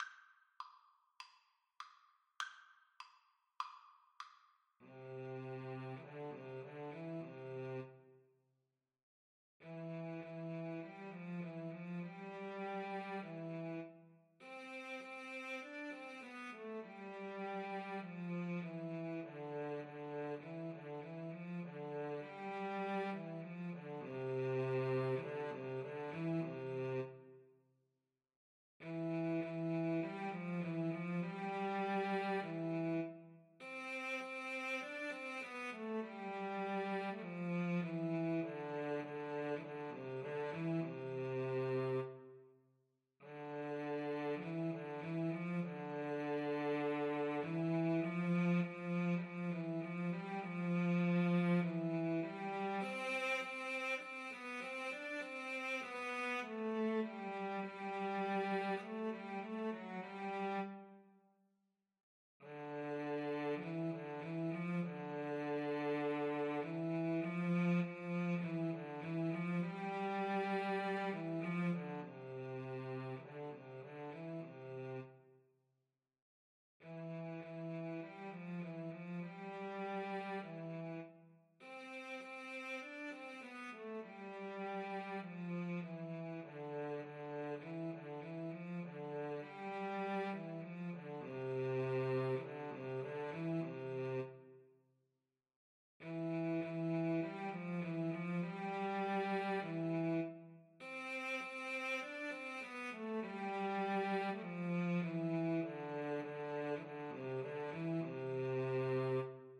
Free Sheet music for Cello Duet
Cello 1Cello 2
4/4 (View more 4/4 Music)
Andante
C major (Sounding Pitch) (View more C major Music for Cello Duet )
Classical (View more Classical Cello Duet Music)
military_sinfoniaVLCduet_kar2.mp3